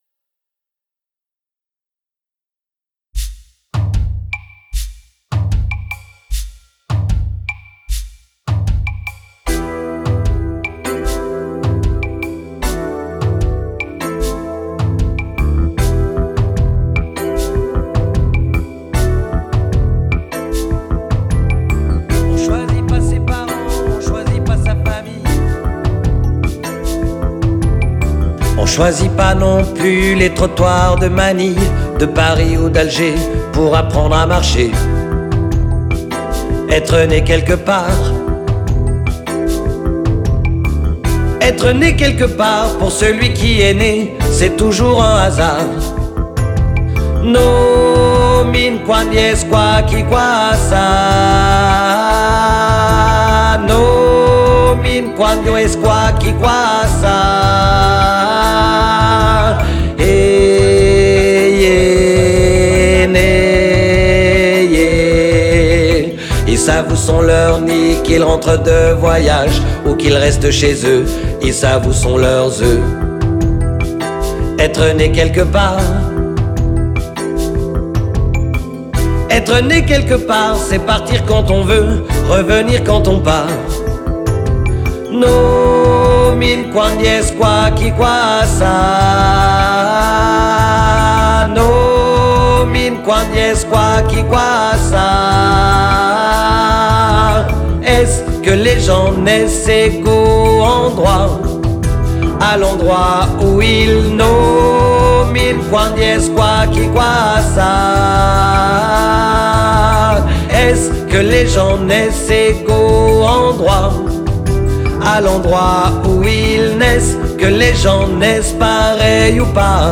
NE QUELQUEPART ALTO